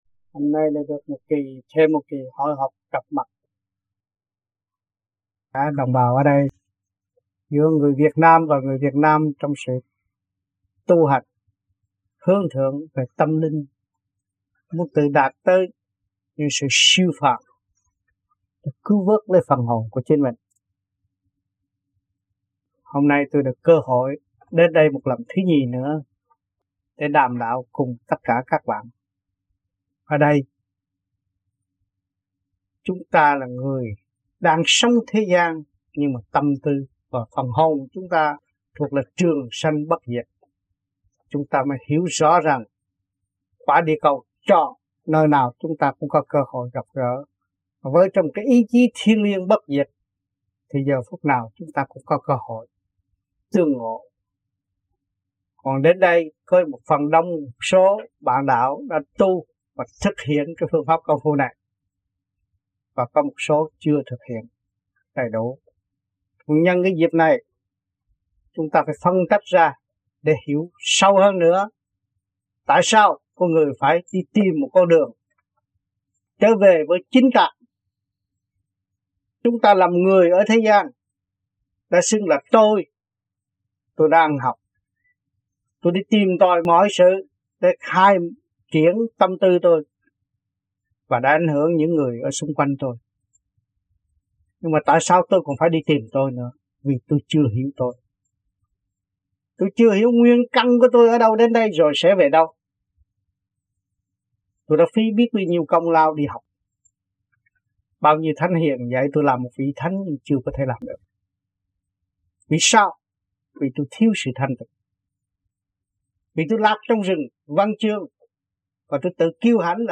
VẤN ĐẠO
THUYẾT GIẢNG